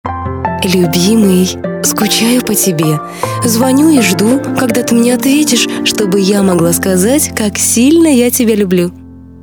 Главная » Файлы » Рингтоны на телефон